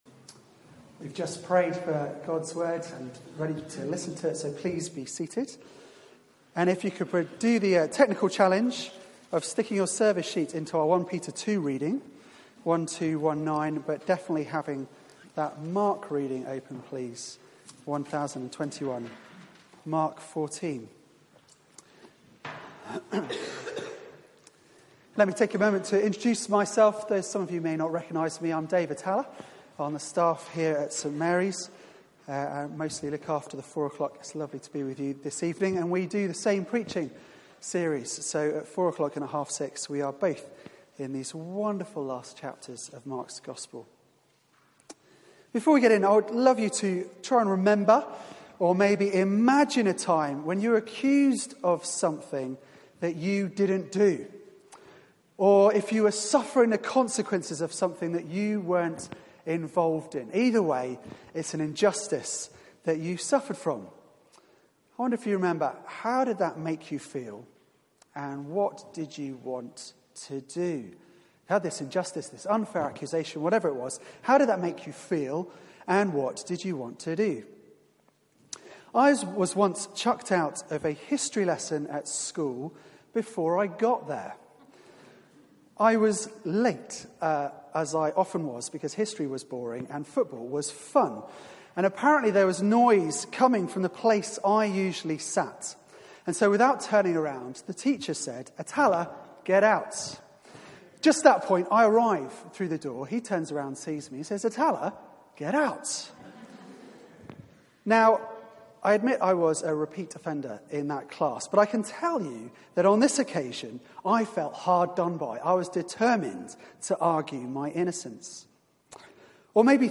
Media for 6:30pm Service on Sun 25th Feb 2018 18:30 Speaker
Sermon